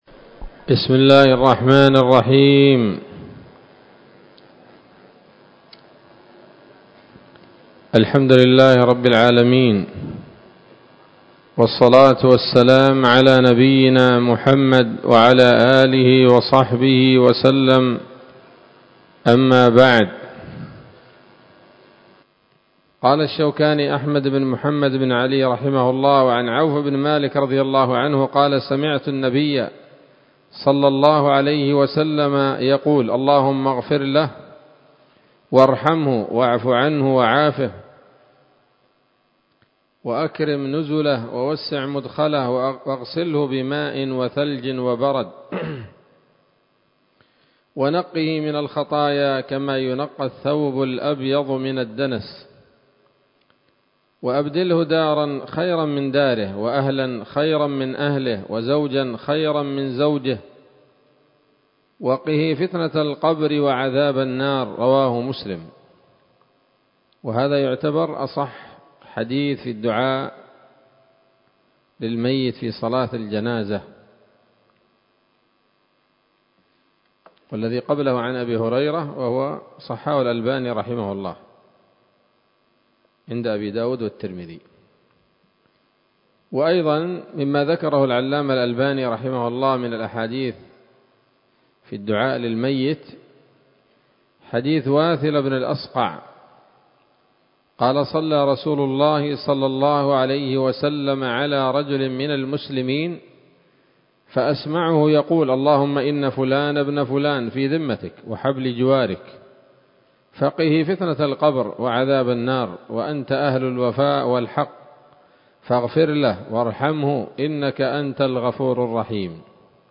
الدرس الخامس من كتاب الجنائز من السموط الذهبية الحاوية للدرر البهية